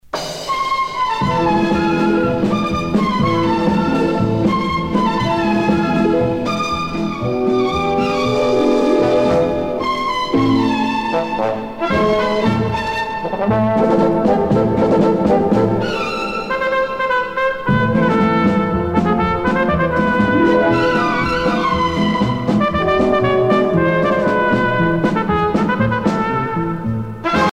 danse : paso-doble
Pièce musicale éditée